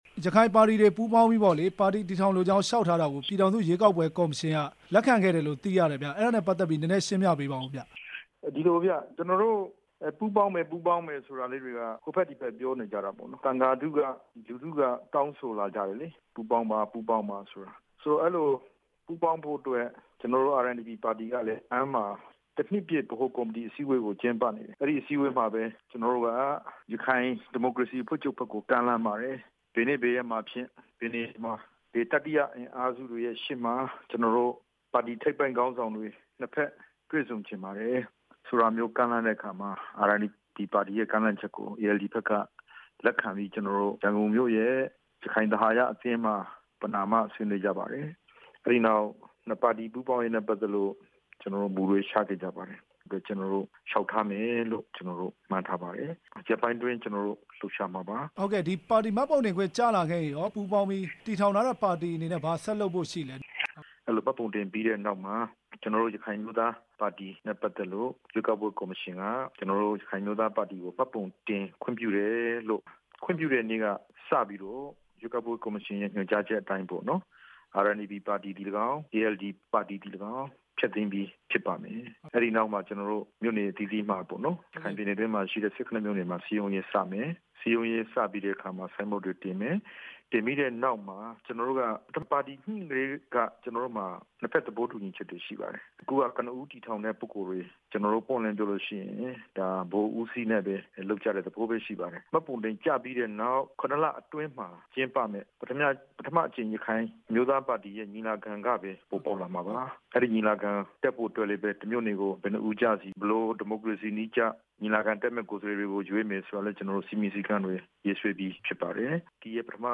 ရခိုင်နှစ်ပါတီ ပူးပေါင်းမှု မေးမြန်းချက်